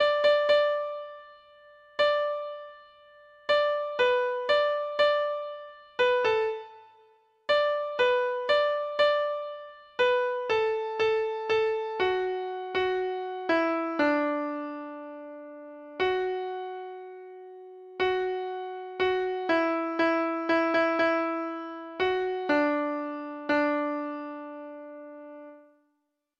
Free Sheet music for Treble Clef Instrument
Traditional Music of unknown author.